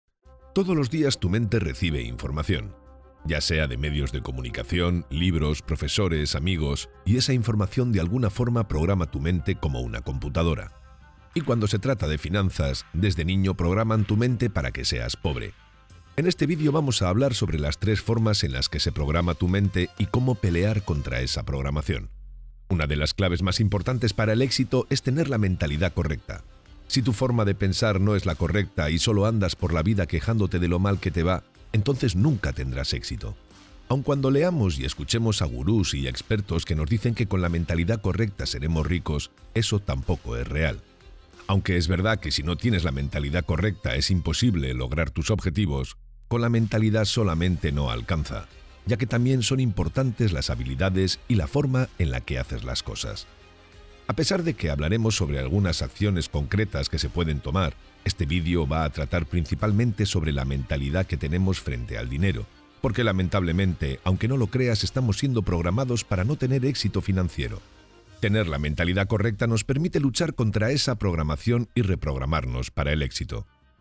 Kein Dialekt
Sprechprobe: Sonstiges (Muttersprache):
Demo locución finanzas.mp3